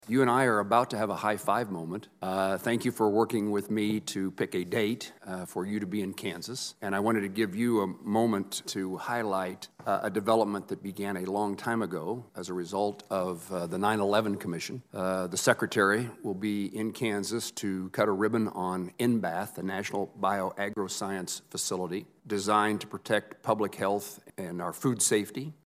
The announcement was first made public during a Senate Appropriations Subcommittee hearing on Agriculture last week by Sen. Jerry Moran to USDA Secretary Tom Vilsack.